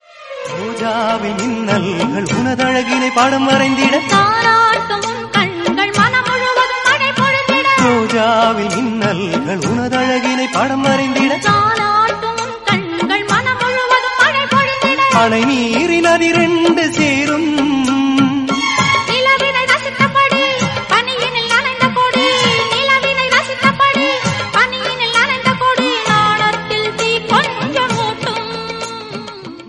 tamil ringtoneemotional ringtonelove ringtonemelody ringtone
best flute ringtone download